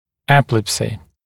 [‘epɪlepsɪ][‘эпилэпси]эпилепсия